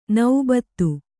♪ naubattu